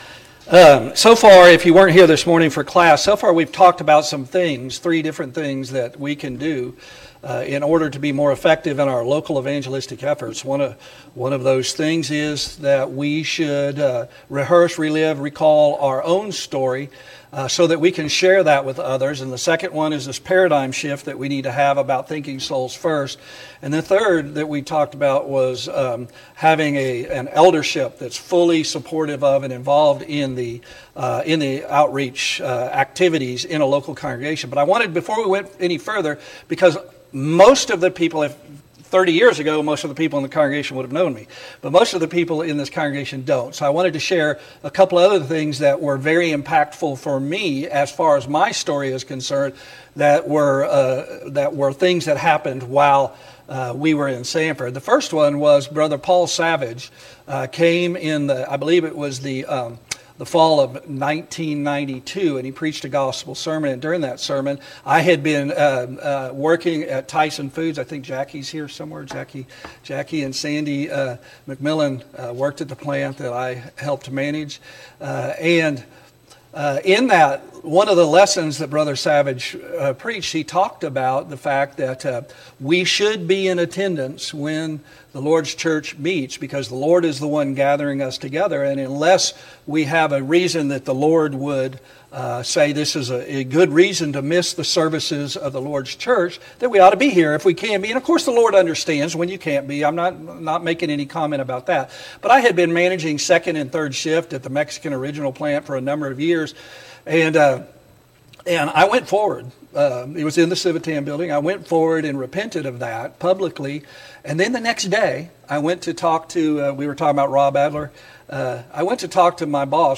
Ephesians 4:16 Service Type: Gospel Meeting « 1.